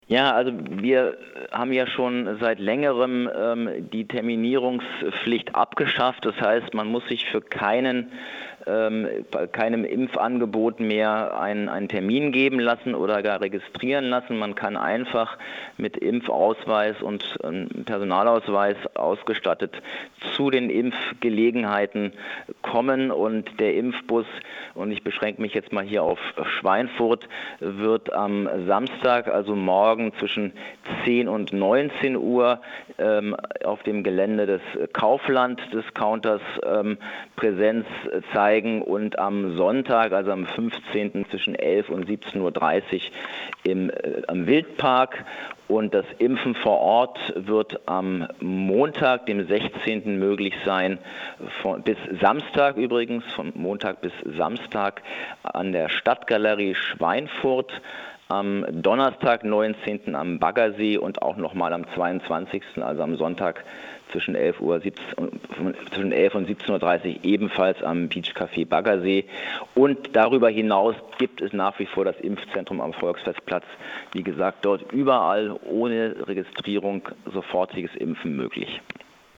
Schweinfurt: 110 Kinder lassen sich Impfen - Im interview mit OB Sebastian Remelé - PRIMATON